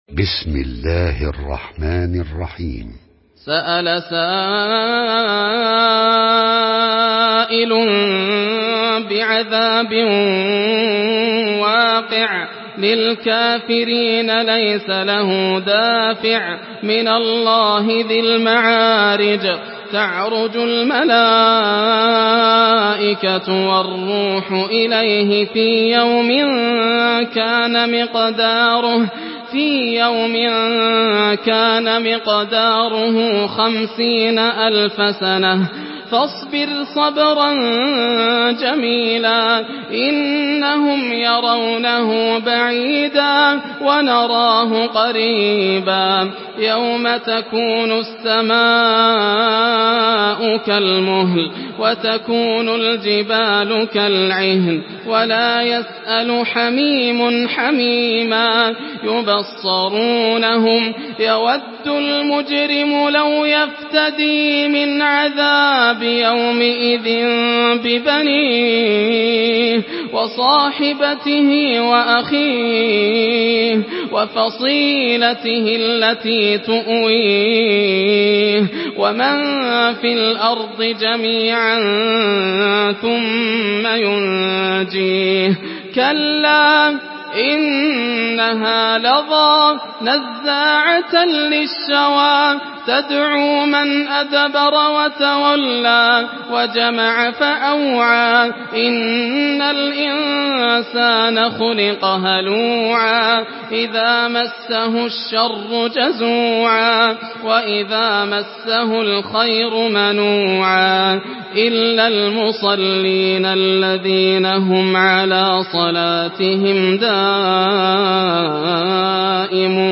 سورة المعارج MP3 بصوت ياسر الدوسري برواية حفص
مرتل